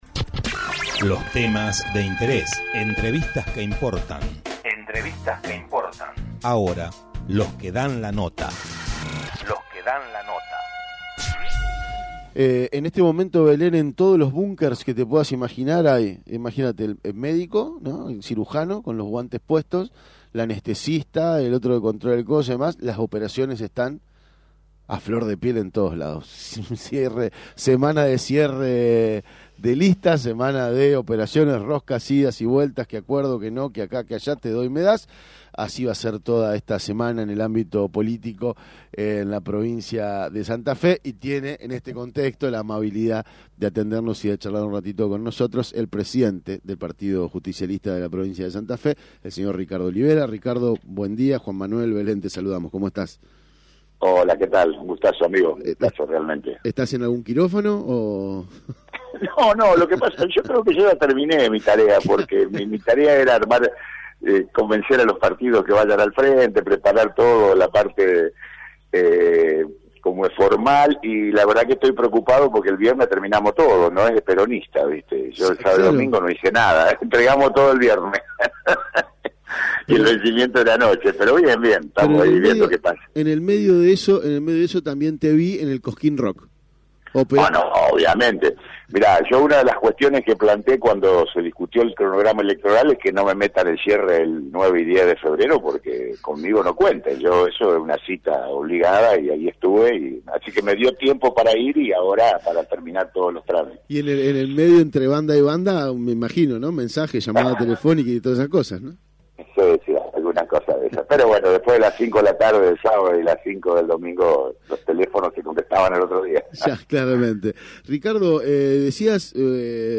En la recta final del cierre de listas en la provincia de Santa Fe, El Hormiguero dialogó con el presidente del PJ, Ricardo Olivera, acerca de la convocatoria del Partido a un amplio frente electoral.